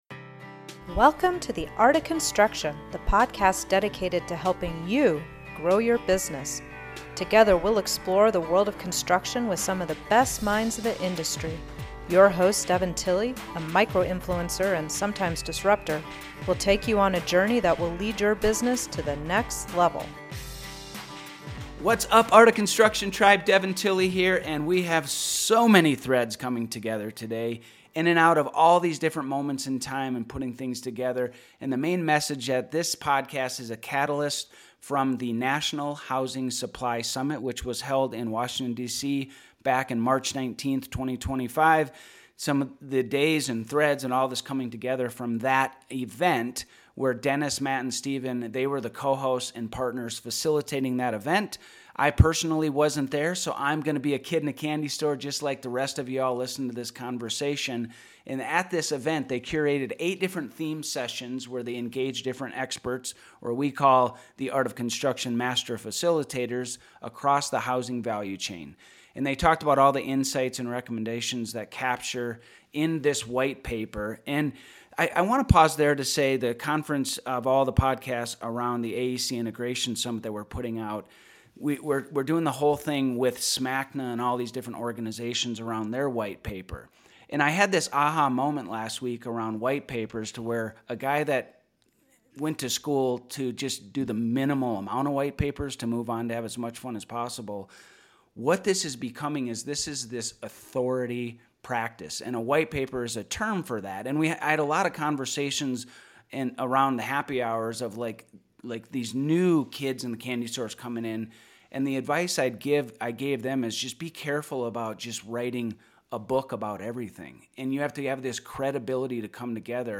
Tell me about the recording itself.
This episode revisits the National Housing Supply Summit in March 2025!